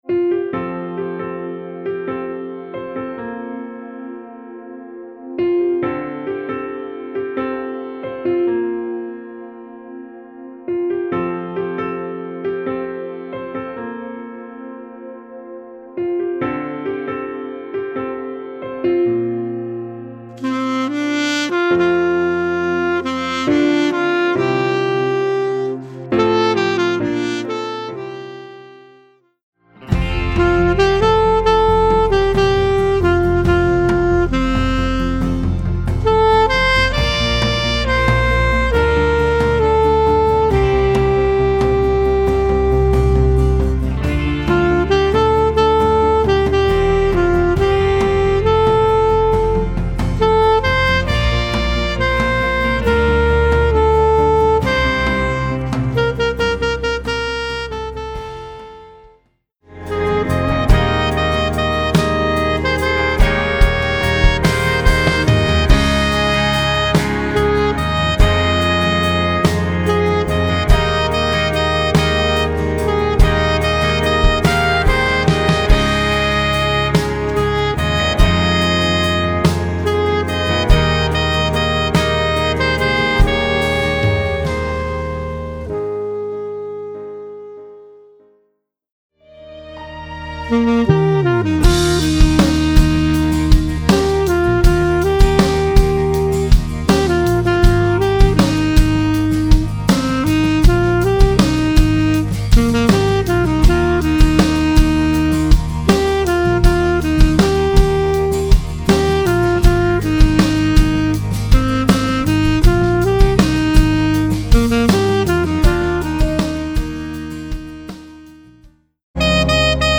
Voicing: Alto Saxophone